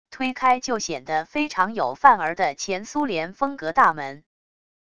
推开就显得非常有范儿的前苏联风格大门wav音频